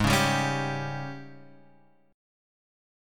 G#M7sus4 Chord